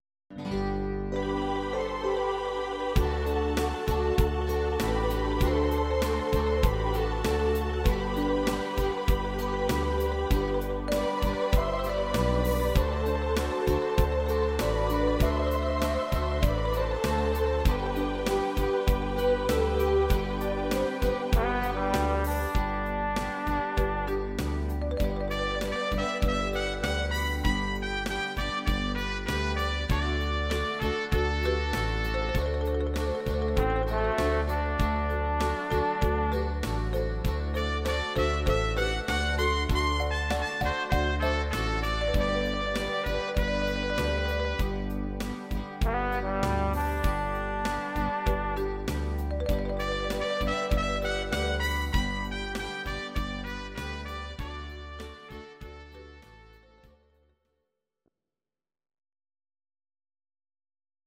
Audio Recordings based on Midi-files
German, Duets, 1980s